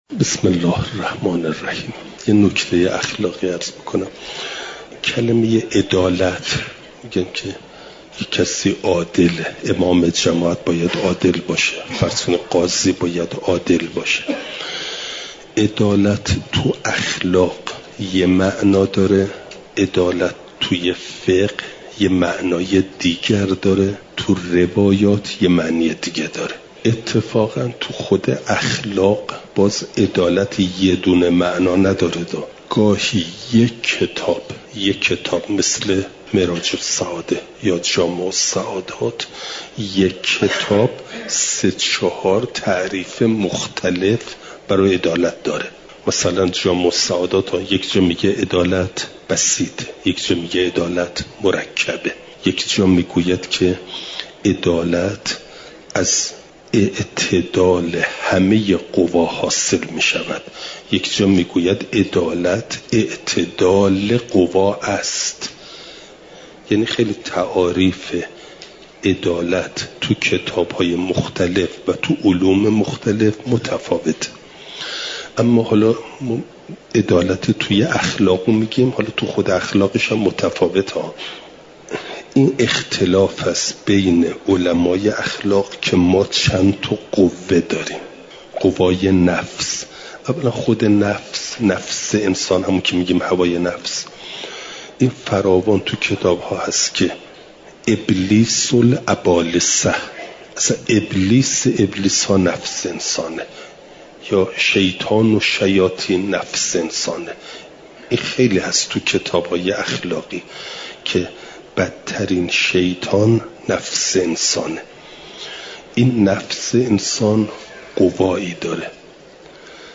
چهارشنبه ۲۶ شهریورماه ۱۴۰۴، حرم مطهر حضرت معصومه سلام ﷲ علیها